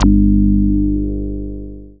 BASS06.WAV